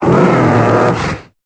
Cri de Bourrinos dans Pokémon Épée et Bouclier.